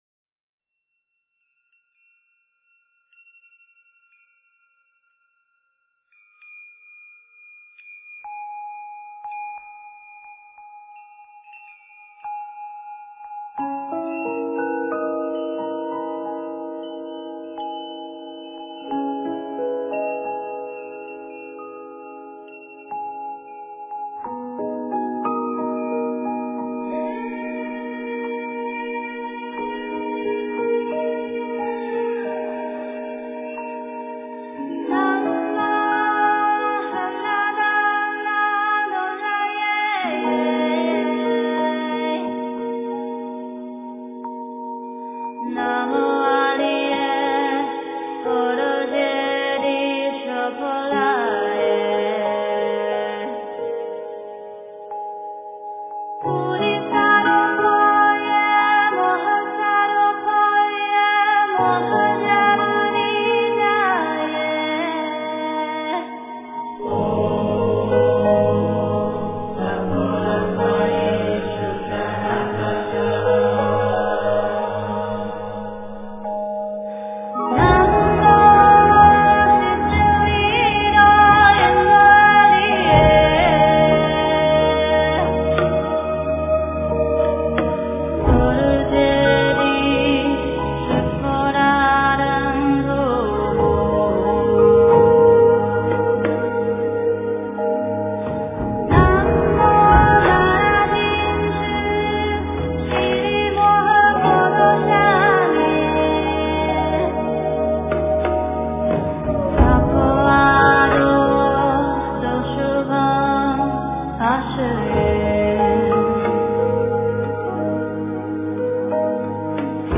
大悲咒 诵经 大悲咒--uri 点我： 标签: 佛音 诵经 佛教音乐 返回列表 上一篇： 心经 下一篇： 大悲咒-藏传 相关文章 娑婆世界--未知 娑婆世界--未知...